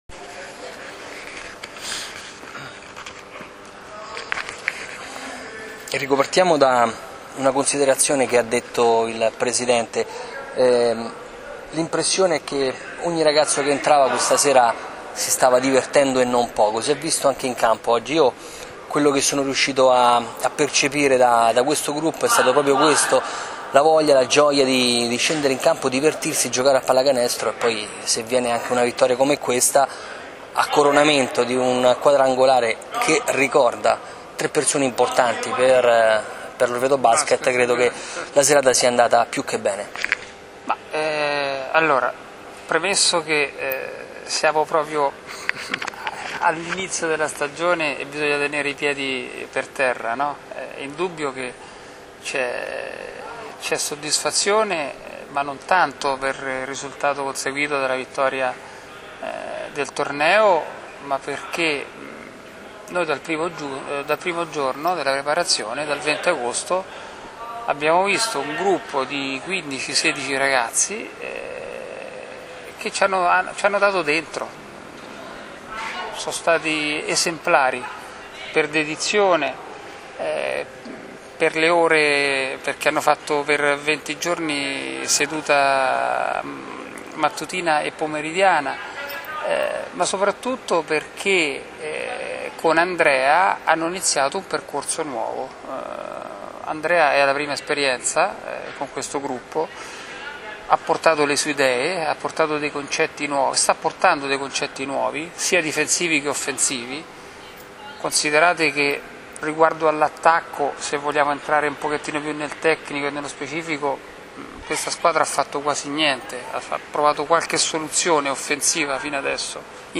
Le interviste del dopo gara